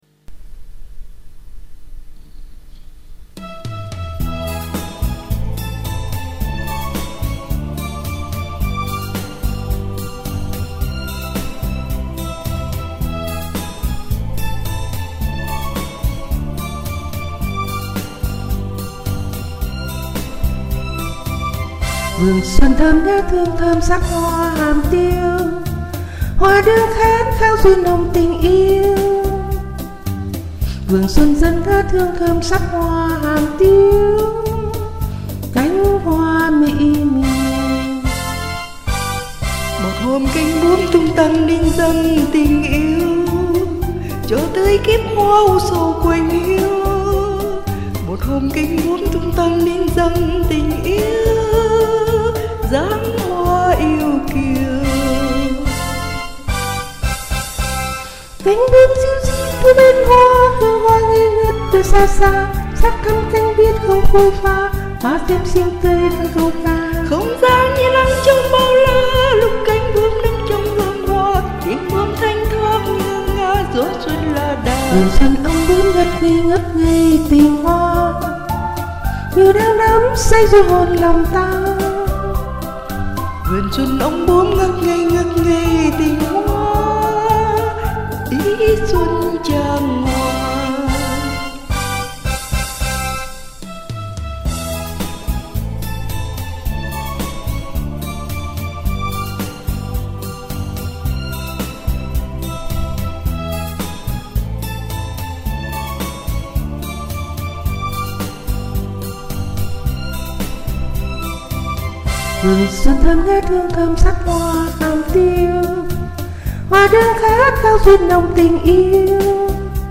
Lần đầu tiên hát nhanh , lưỡi co quắp khó quá hihi